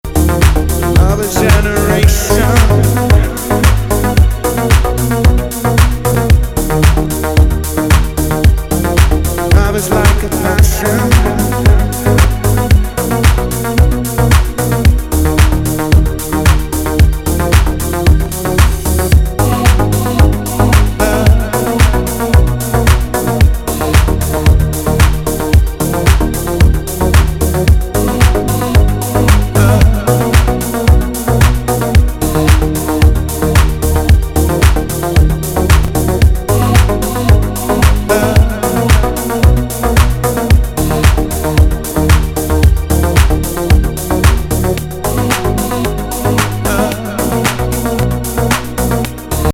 のプロデューサーによる、海外でも非常に人気の一枚!男性ボーカルの
バレアリック・ブギー・ナンバー。